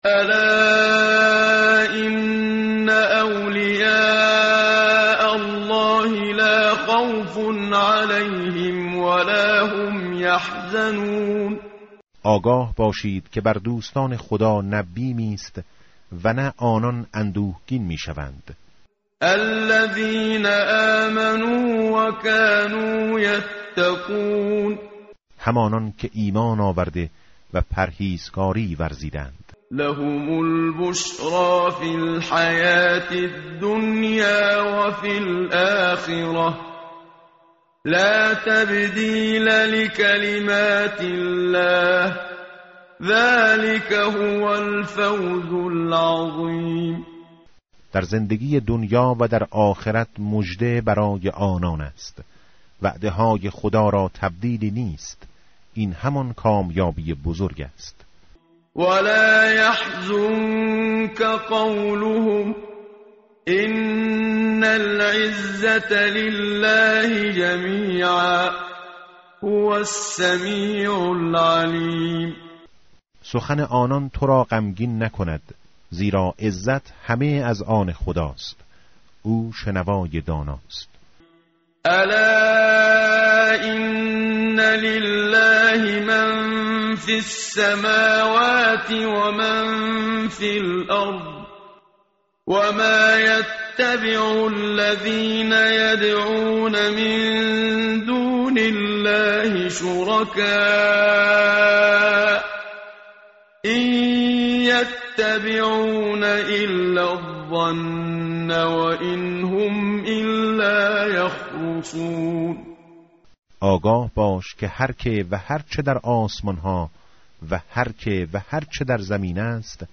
tartil_menshavi va tarjome_Page_216.mp3